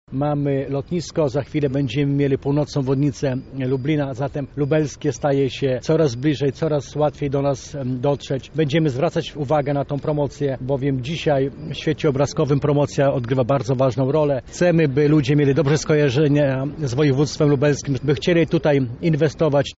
Tysiąc plakatów zawiśnie w największych miastach Polski. „ Nasze hasło to: Na chwilę, lub na dłużej „ – mówi Krzysztof Hetman, marszałek województwa lubelskiego.